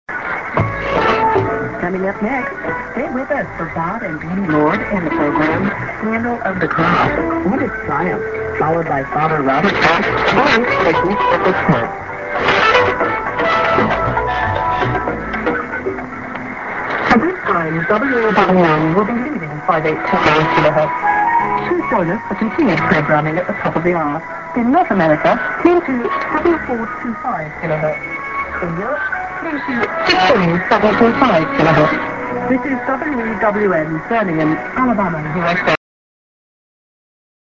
ID+SKJ(women)-> S/off